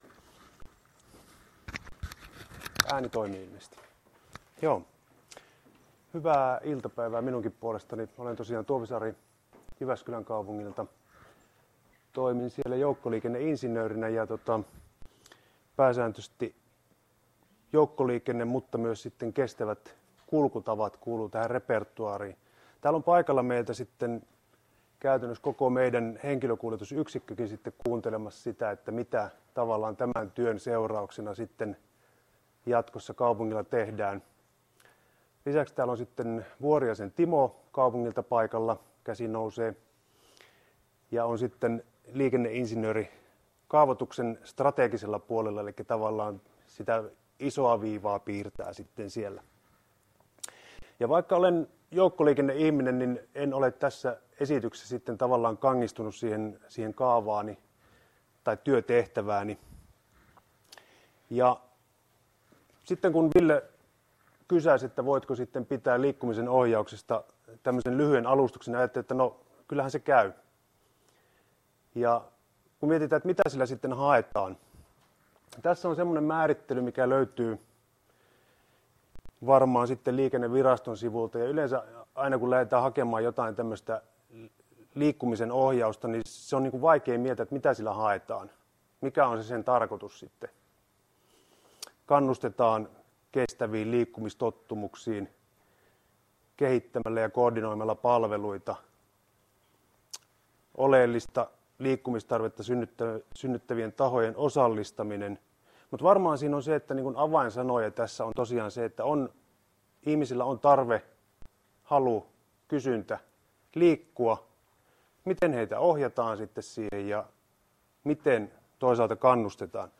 Kestävä työmatkaliikkuminen -hankkeen loppuseminaari